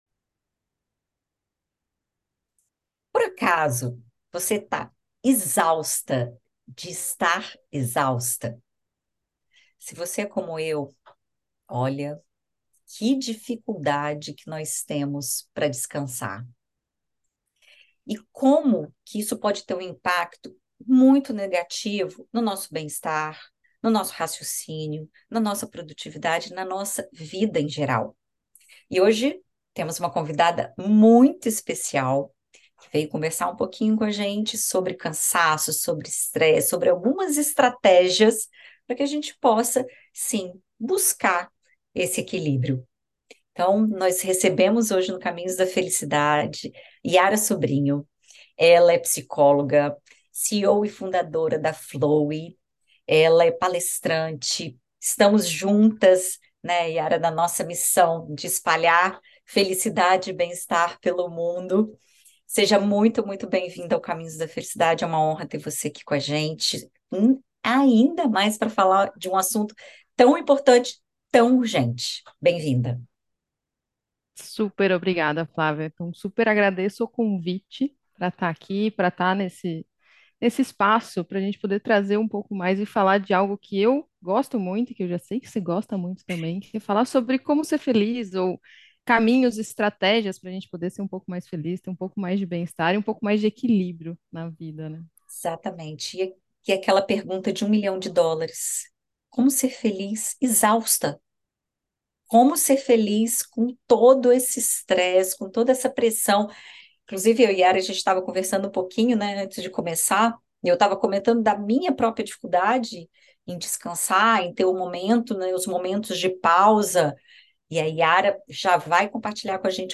comenta com uma psicóloga sobre o equilíbrio entre a exaustão e o bem-estar para descobrir o verdadeiro caminho da felicidade.